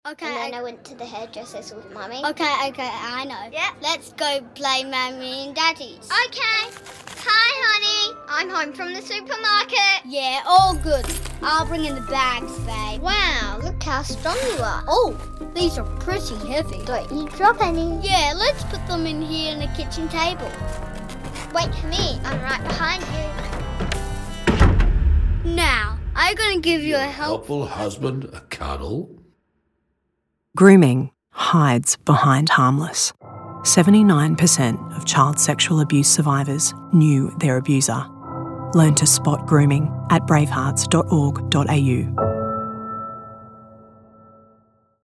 Radio
The voice talent heard in the three chilling radio spots came from Brisbane-based voiceover agency SnackBox.